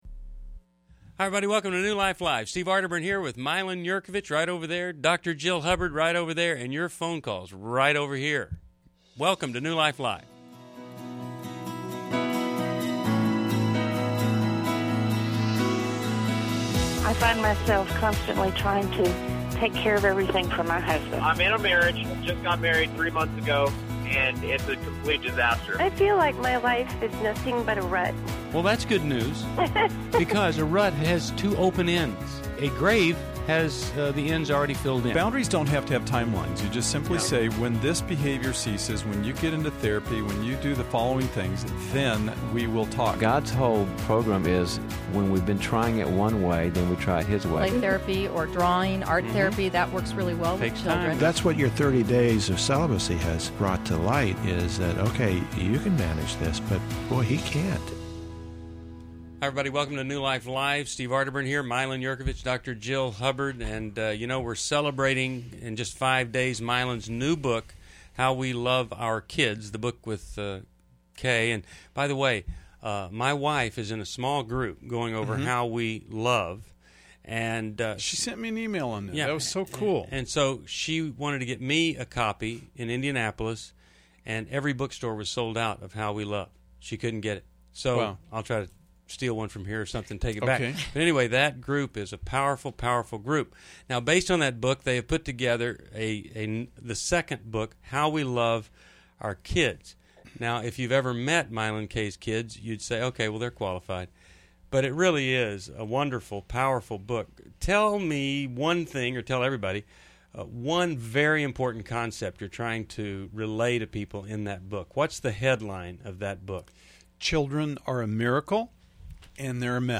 Caller Questions: 1. My ex wants to leave her new husband and reconcile; good idea? 2. Should I grieve the loss of never having kids? 3. My 16yo husband won’t stop talking about his previous girlfriends. 4. I lost desire for sexual intimacy with my husband. 5.